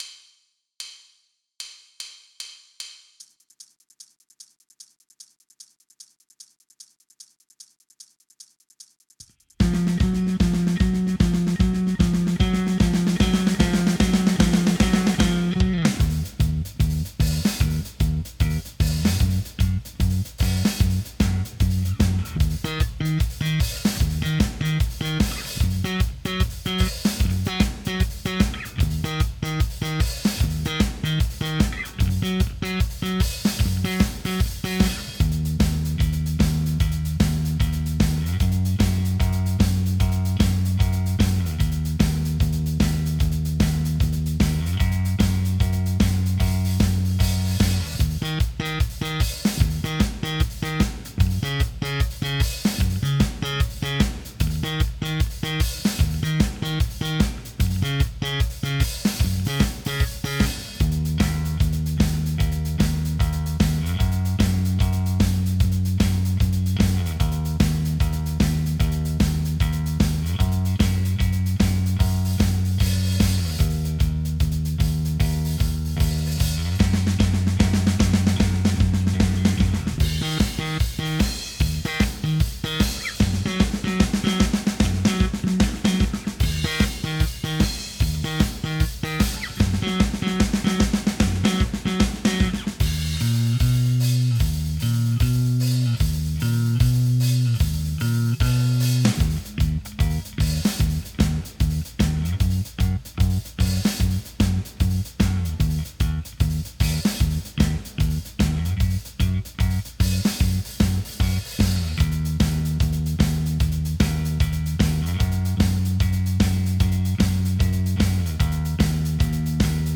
Without vocals
Based on the album and rare live version